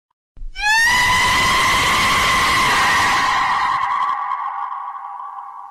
Chicken On Tree Screaming